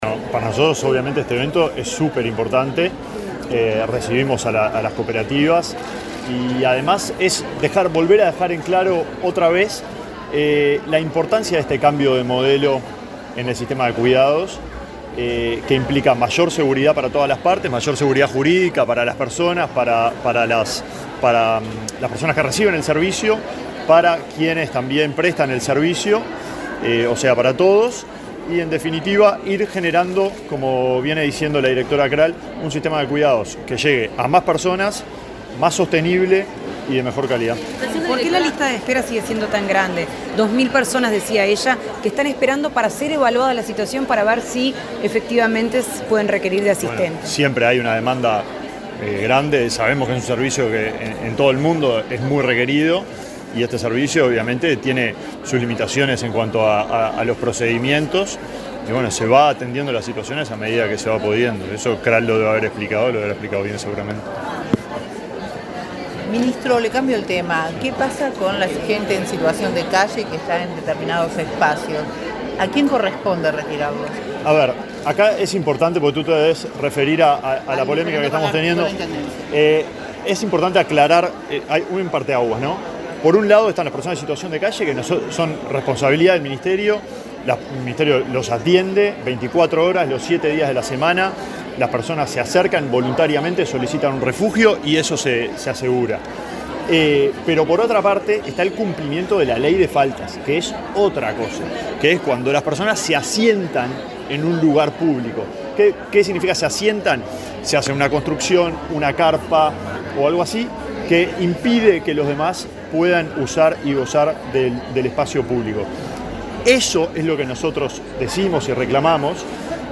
Declaraciones del ministro de Desarrollo Social, Alejandro Sciarra
El ministro de Desarrollo Social, Alejandro Sciarra, fue entrevistado para medios informativos, antes de participar, este jueves 18 en Montevideo, en